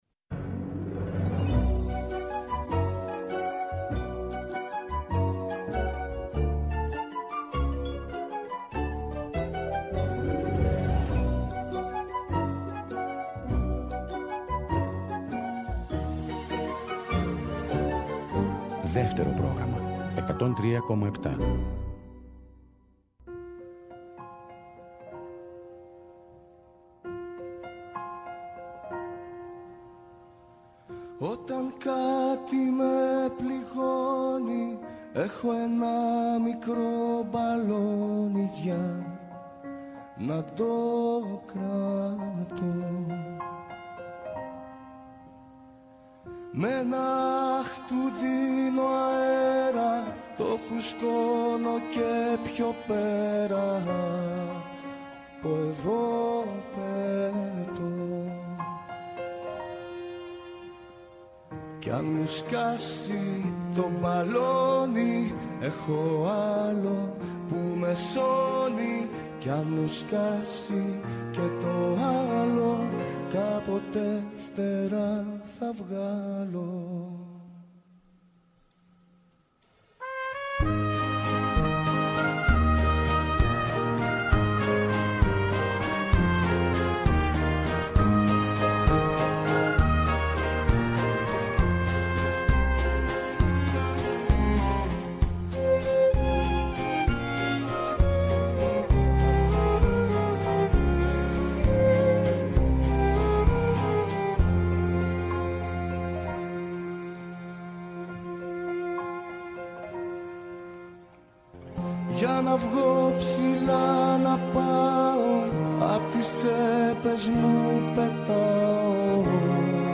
“Ροκ συναναστροφές” με το …”ροκ” όχι μόνο ως μουσική φόρμα, αλλά περισσότερο ως στάση ζωής. Αγαπημένοι δημιουργοί και ερμηνευτές αλλά και νέες προτάσεις, αφιερώματα και συνεντεύξεις, ο κινηματογράφος, οι μουσικές και τα τραγούδια του.